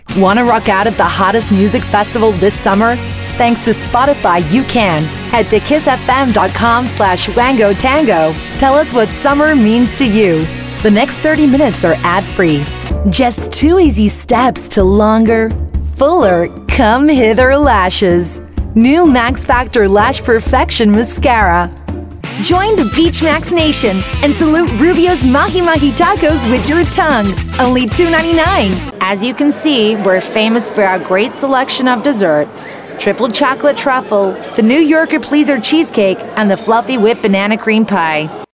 Female
Yng Adult (18-29), Adult (30-50)
Compilation Of Radio/ Tv/ Spots
U.S Accent Reel